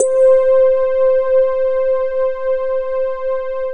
Keys (7).wav